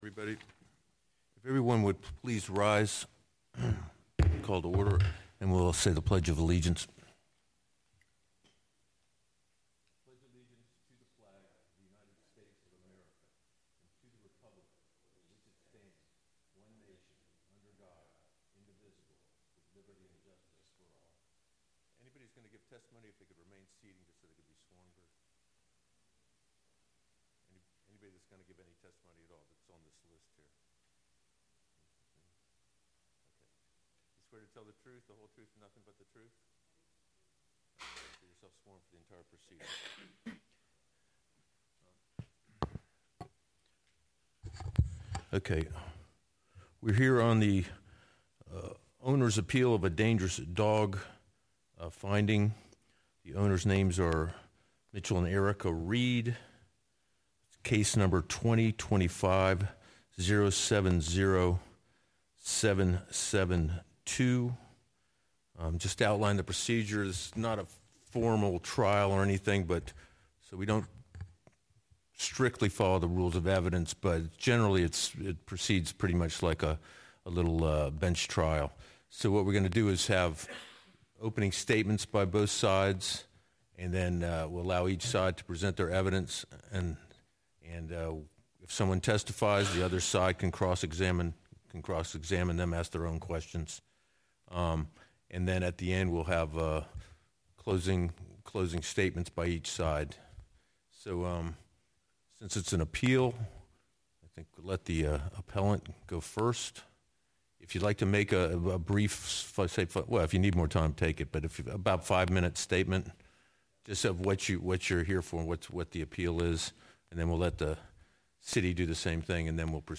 Animal Control Special Hearing media for Tuesday, September 30, 2025 | Palm Coast Connect
< Back to 2025 Meetings Animal Control Special Hearing Tuesday, September 30, 2025 City Hall Download/View Agenda Want to view this meeting's agenda?